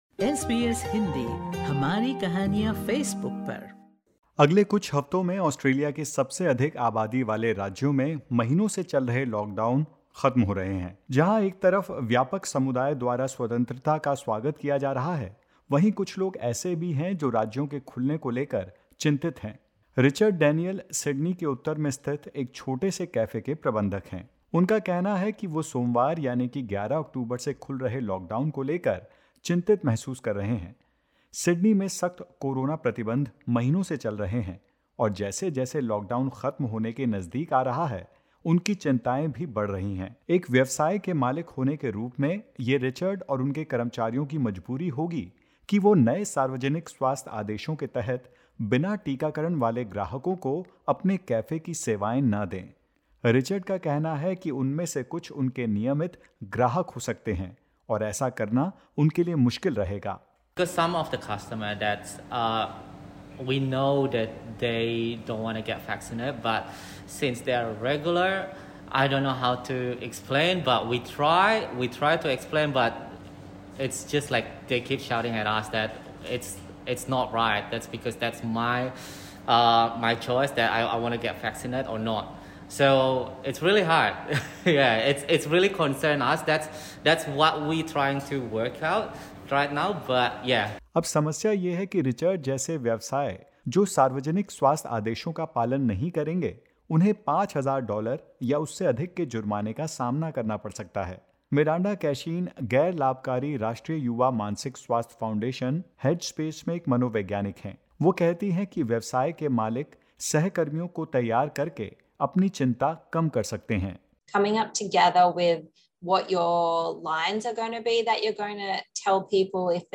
सुनते हैं इस रिपोर्ट में।